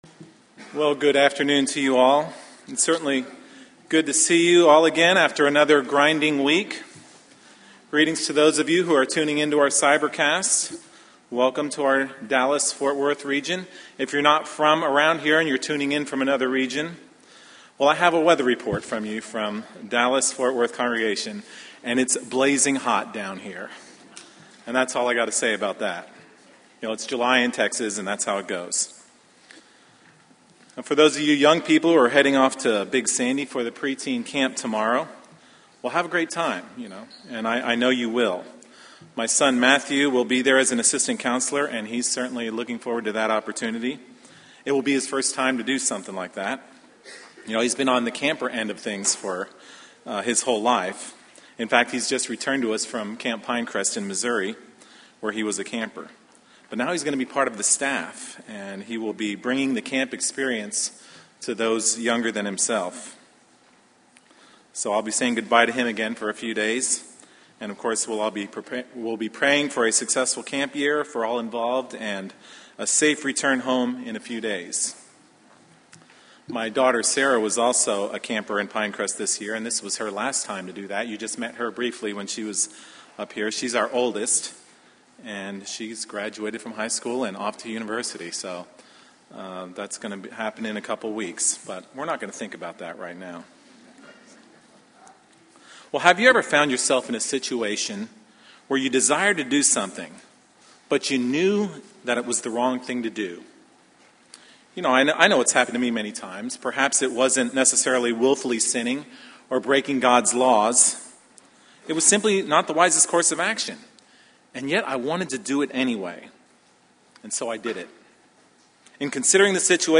The sermon will explore the faulty line of reasoning in thinking one can sin now and repent later with no consequences. We will explore the historical setting of Jeremiah's day and the decades of King Manasseh's evil reign that established the culture of Jeremiah's time and ministry.